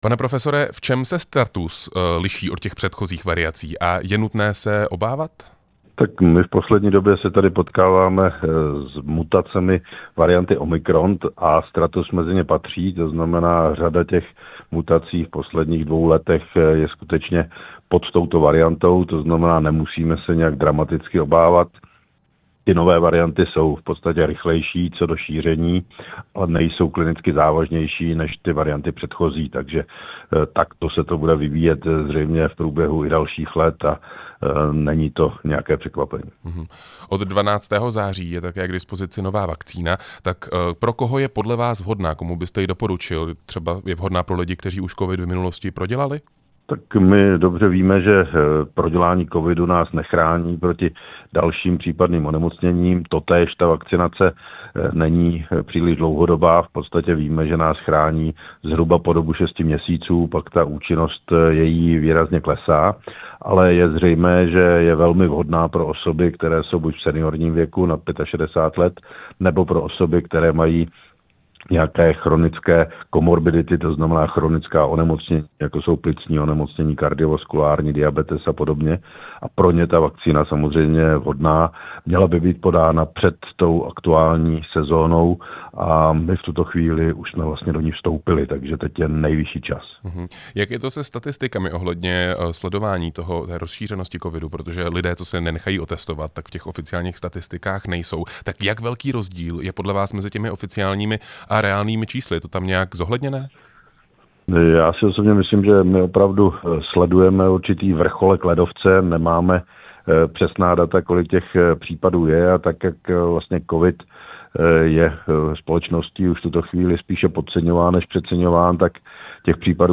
Rozhovor s vakcinologem Romanem Prymulou